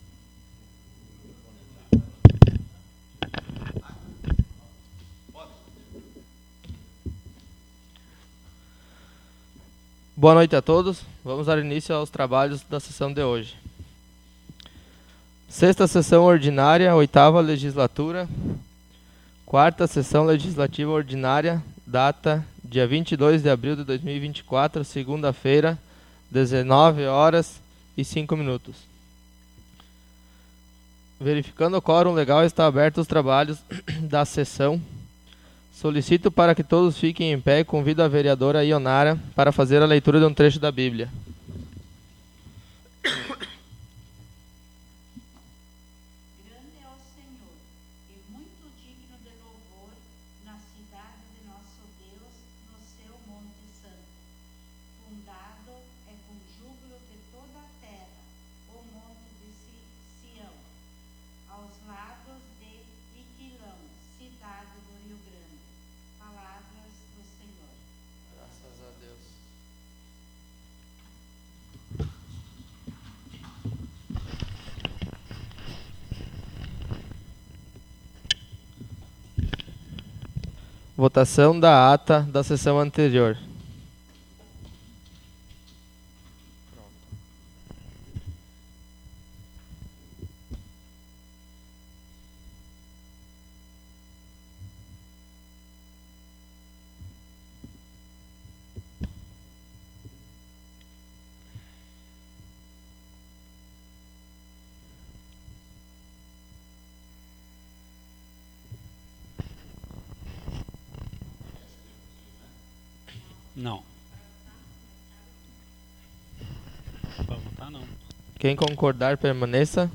Audio da 6ª Sessão Ordinária 22.04.24